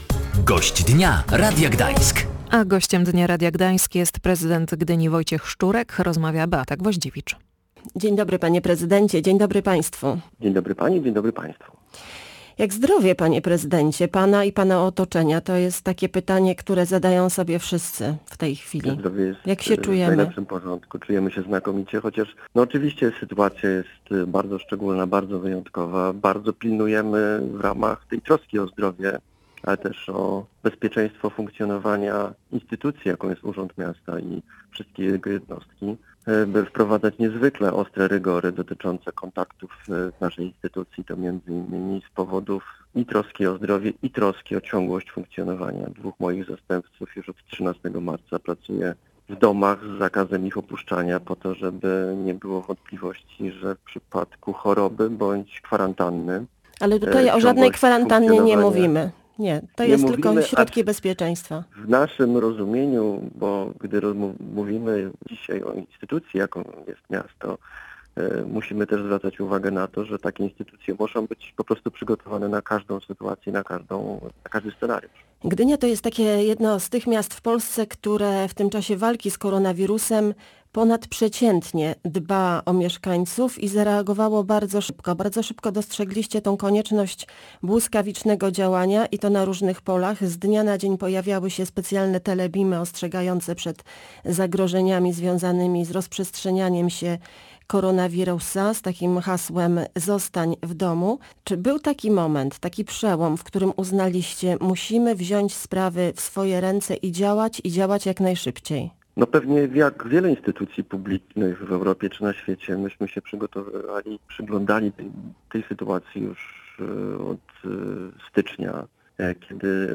pytała prezydenta Gdyni Wojciecha Szczurka, który był Gościem Dnia Radia Gdańsk.